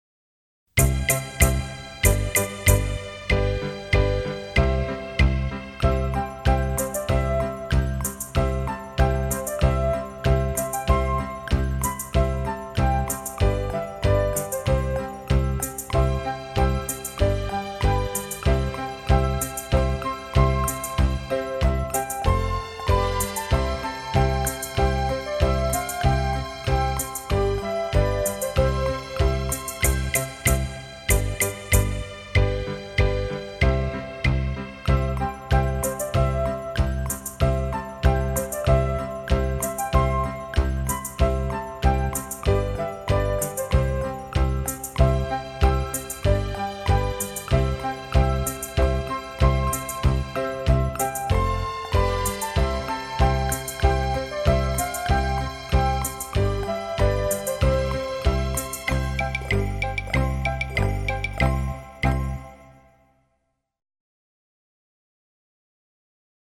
伴唱音乐